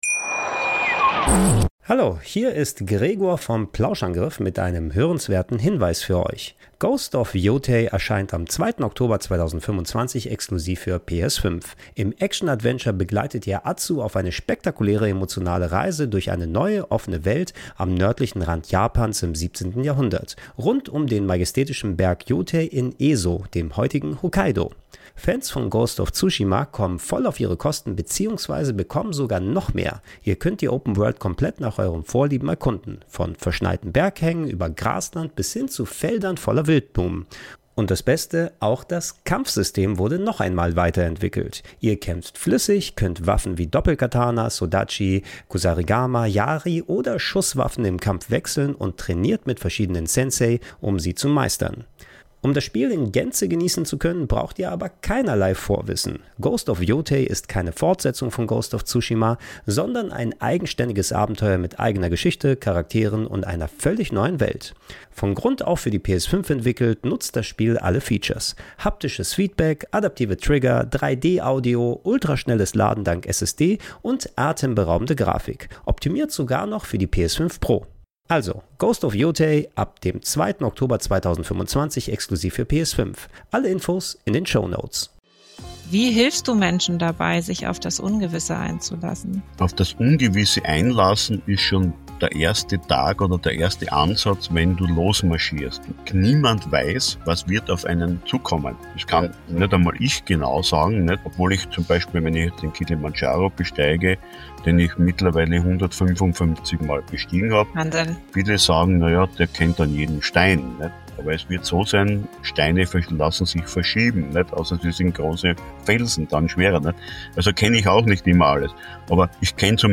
Diese Folge ist ruhig und kraftvoll.